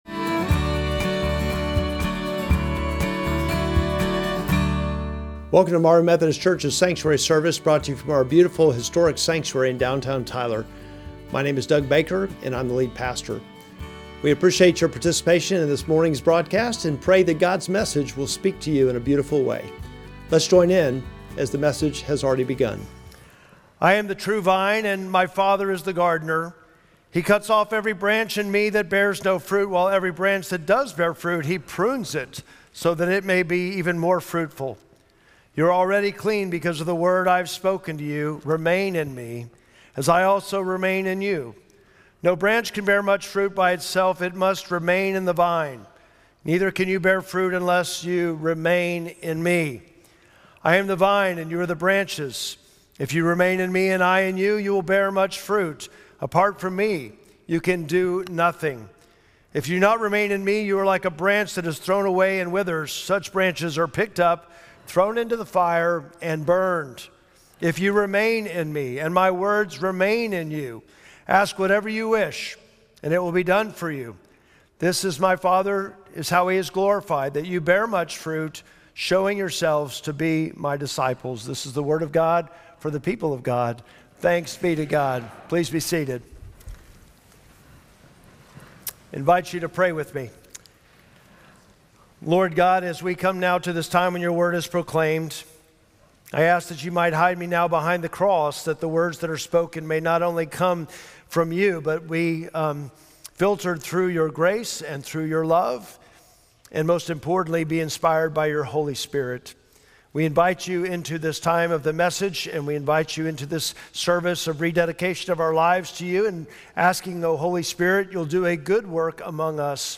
Sermon text: John 15:1-8